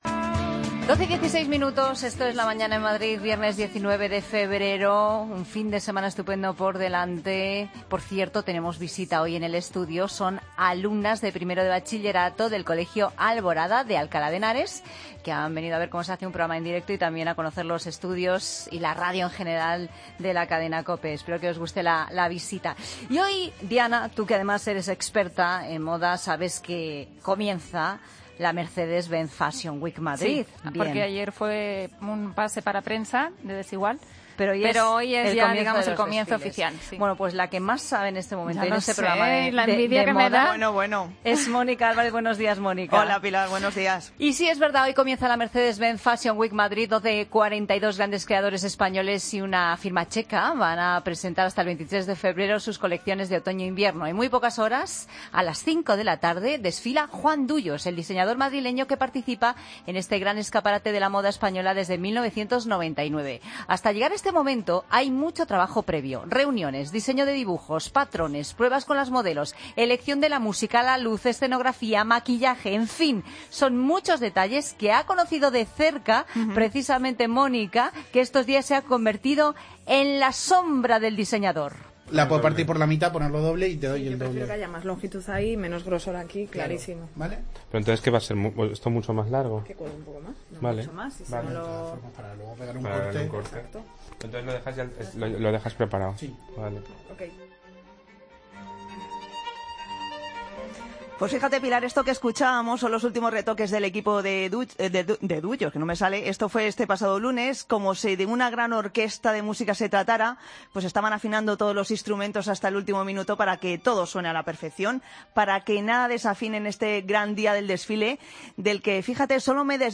Escucha al diseñador Juan Duyos en La Mañana en Madrid